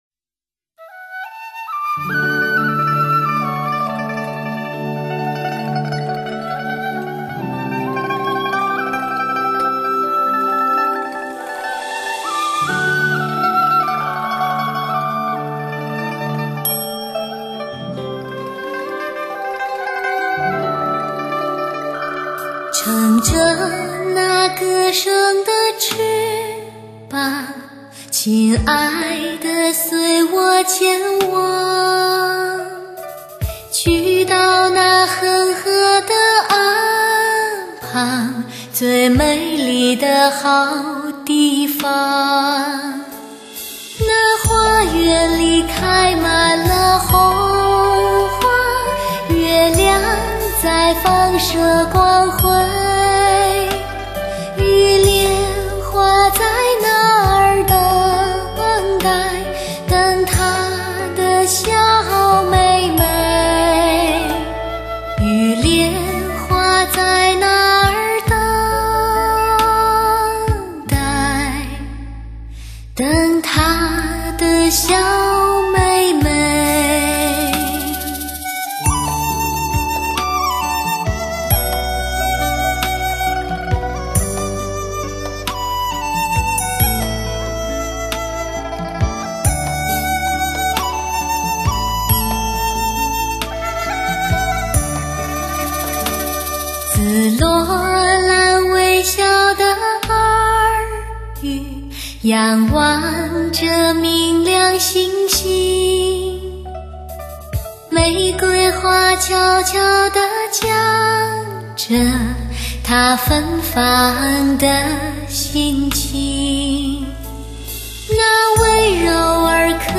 中国民乐演绎经典的奥运歌曲往届奥运会举办国的经典民歌 ★ 中国民乐与世界音乐的一次伟大融合